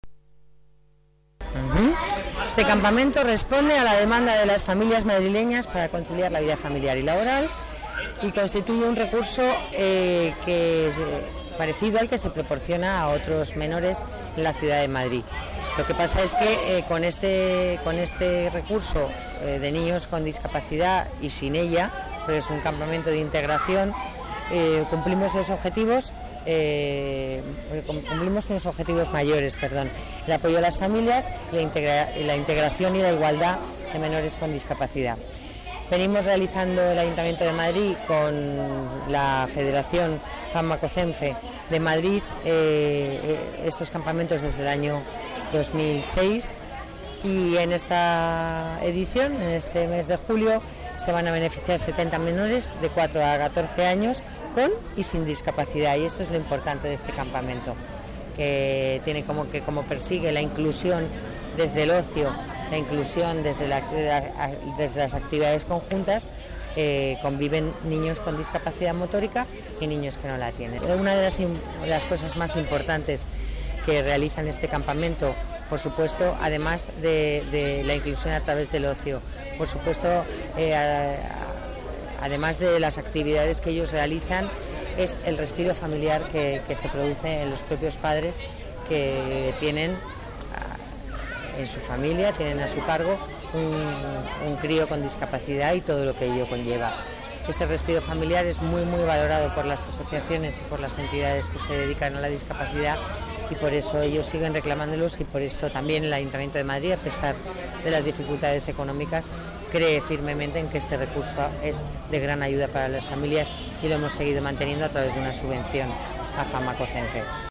Nueva ventana:Declaraciones de la delegada de Familia, Servicios Sociales y Participación Ciudadana, Lola Navarro: Campamentos de Integración